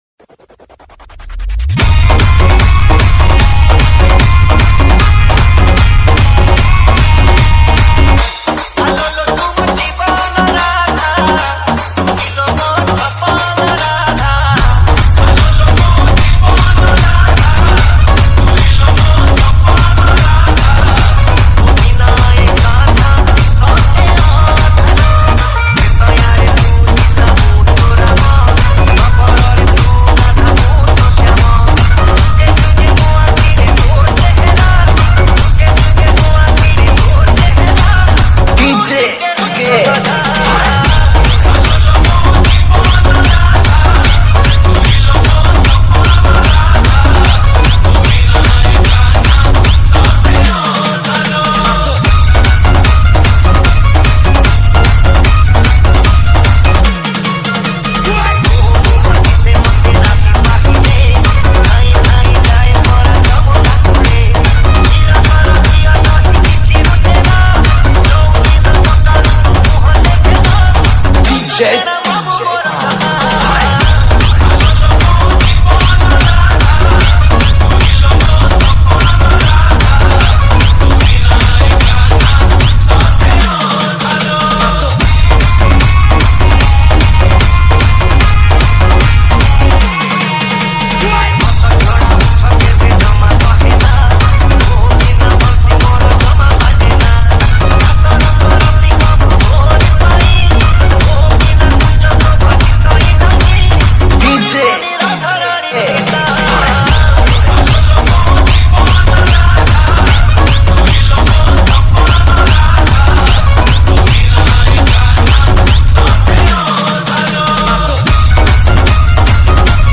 ODIA BHAJAN DJ REMIX